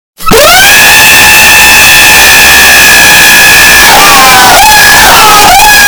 Larry The Screaming Bird